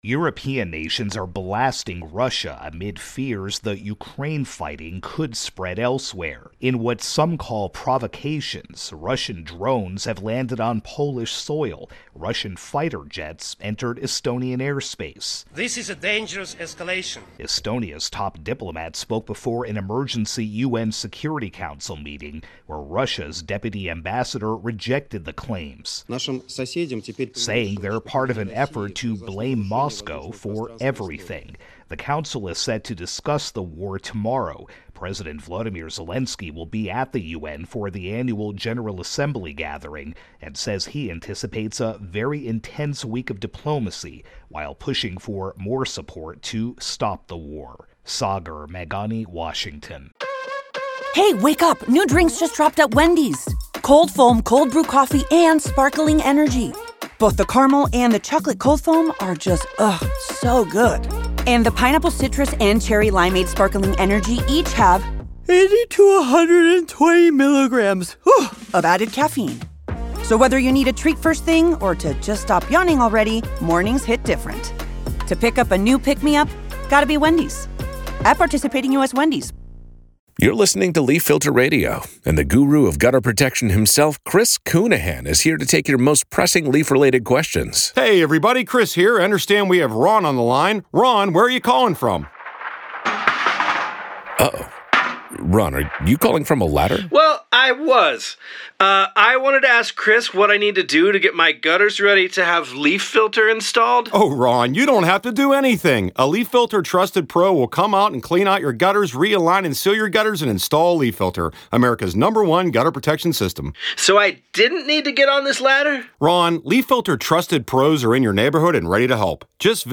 reports on European fears that the Russia-Ukraine war will spread.